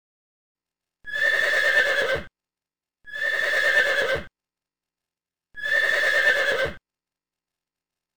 Horse Téléchargement d'Effet Sonore
Horse Bouton sonore